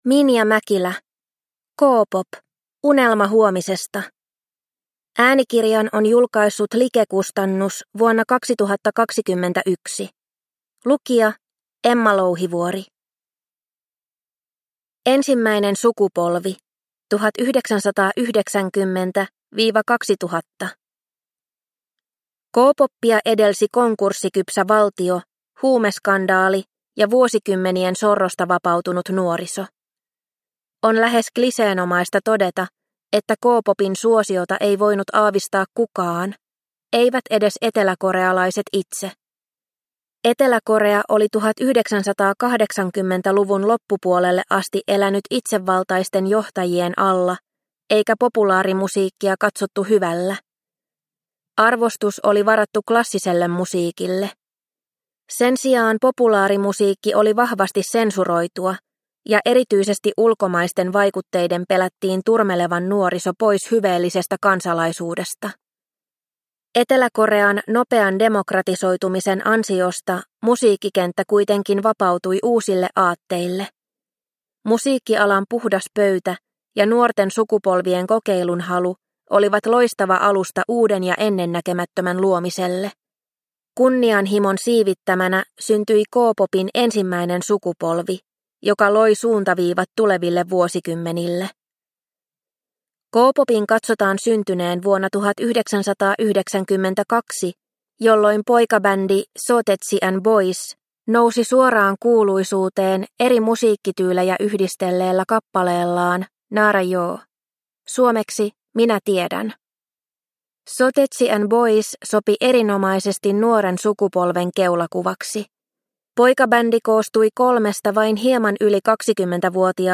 K-pop - Unelma huomisesta – Ljudbok – Laddas ner